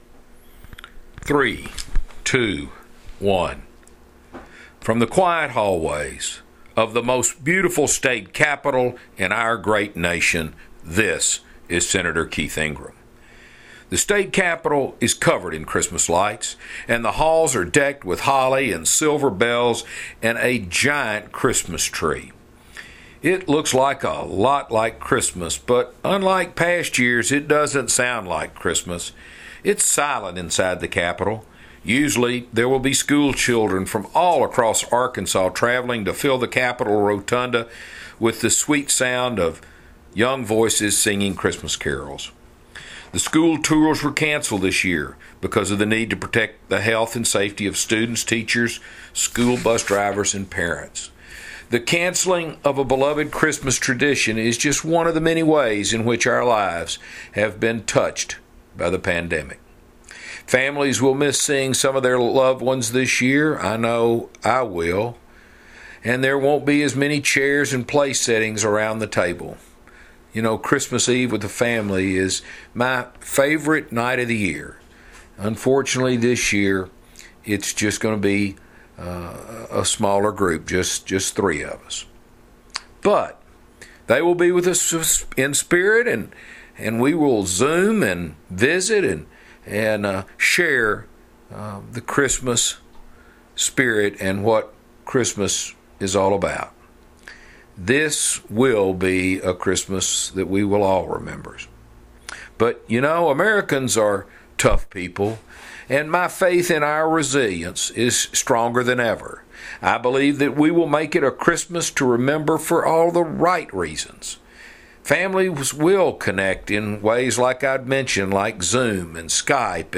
Weekly Address - Merry Christmas!